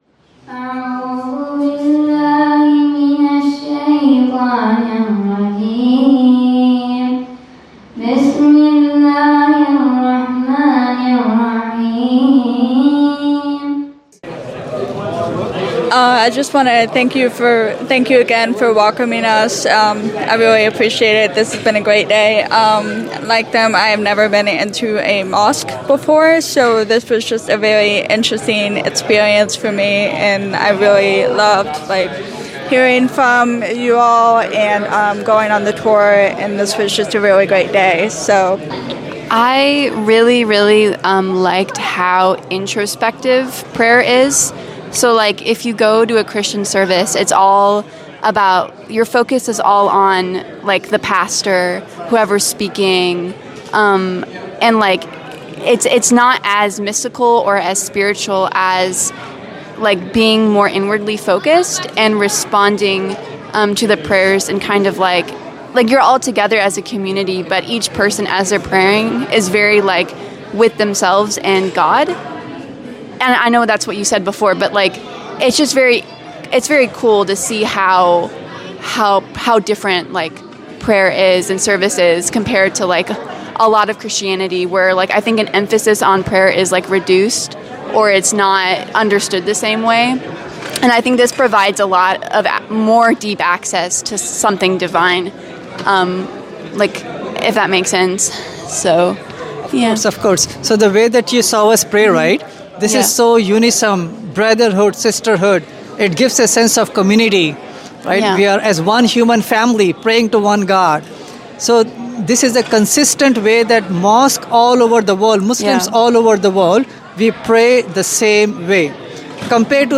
4 Young CHRISTIAN Vs.1 MUSLIM – A passionate discussion in a Masjid.mp3